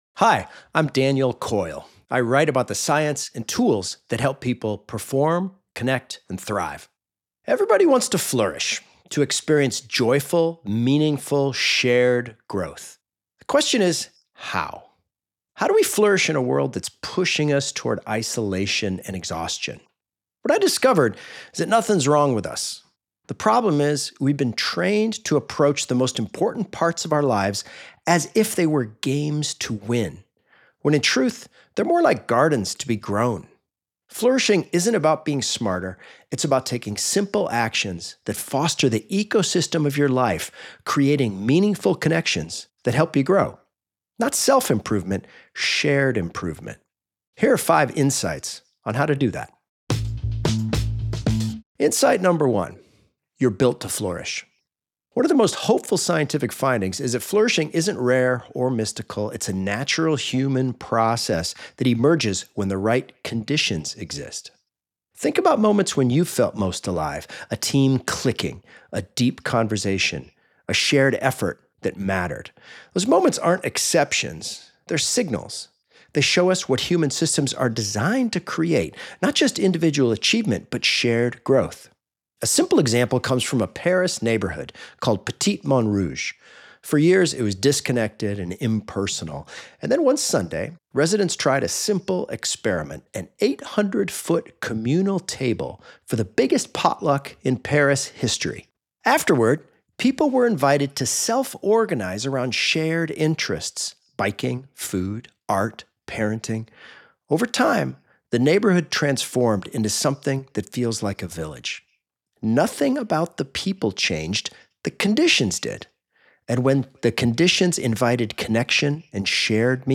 Listen to the audio version of this Book Bite—read by Daniel himself—below, or in the Next Big Idea App.